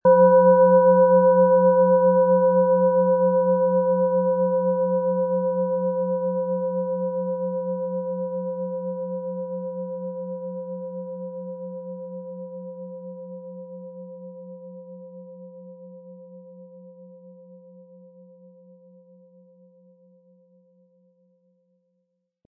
Planetenschale® Lebensfreude wahrnehmen & Fühle Dich wohl mit Jupiter & DNA-Ton, Ø 15,8 cm, 400-500 Gramm inkl. Klöppel
Planetenton 1
Nach uralter Tradition von Hand getriebene Klangschale.
• Mittlerer Ton: DNA
Im Sound-Player - Jetzt reinhören können Sie den Original-Ton genau dieser Schale anhören.